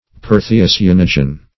Perthiocyanogen \Per*thi`o*cy*an"o*gen\, n. (Chem.)